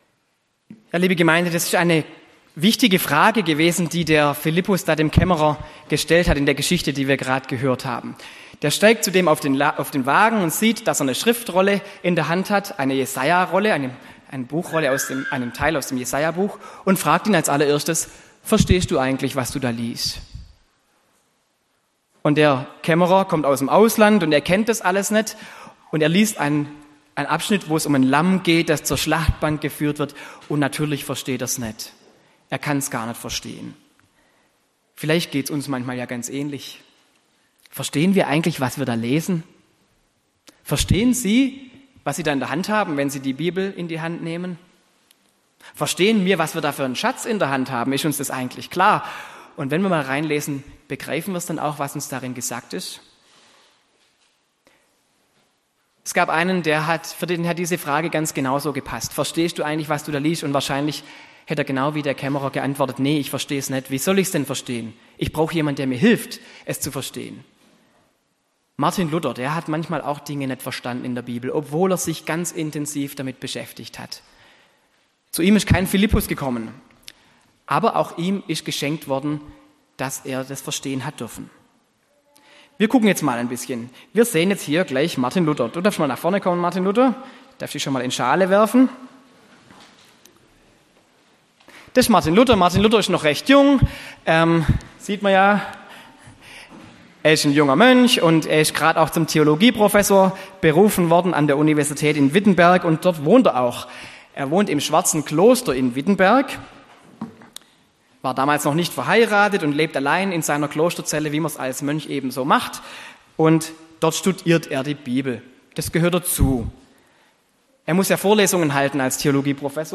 Predigt am Reformationsfest: Gott schenkt, dass wir ihn finden können
predigt-am-reformationsfest-gott-schenkt-dass-wir-ihn-finden-koennen